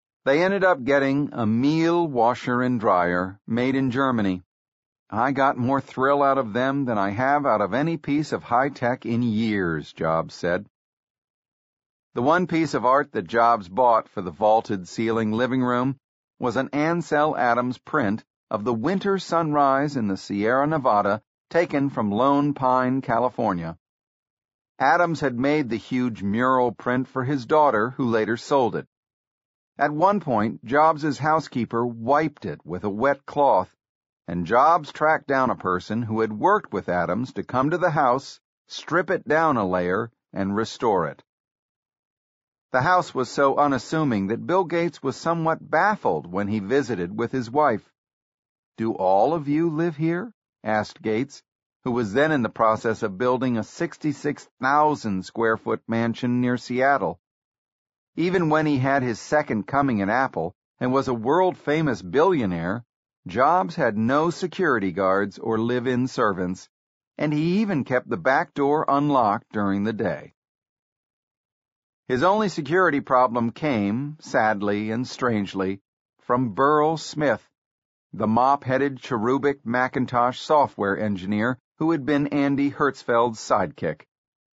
在线英语听力室乔布斯传 第316期:安家(5)的听力文件下载,《乔布斯传》双语有声读物栏目，通过英语音频MP3和中英双语字幕，来帮助英语学习者提高英语听说能力。
本栏目纯正的英语发音，以及完整的传记内容，详细描述了乔布斯的一生，是学习英语的必备材料。